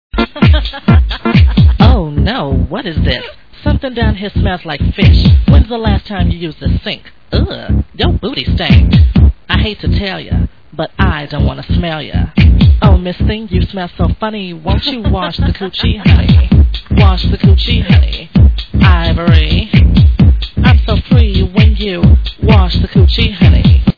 Sounds like an older track to me.
Very old IMO.